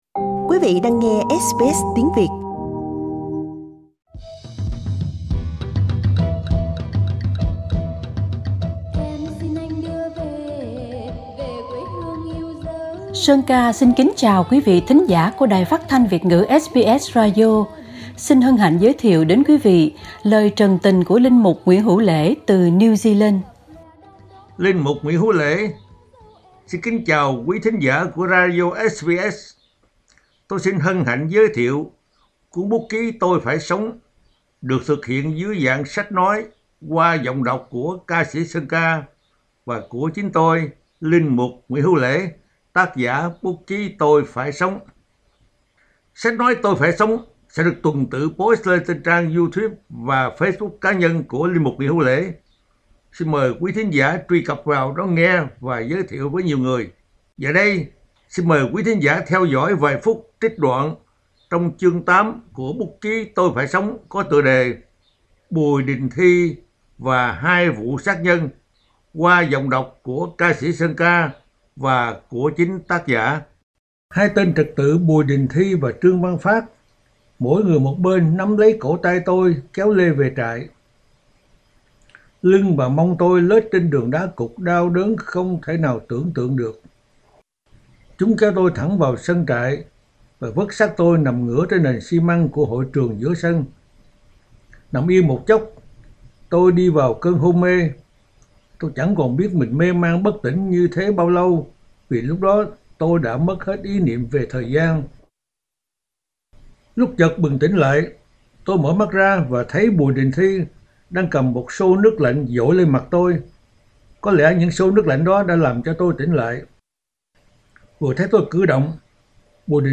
sách nói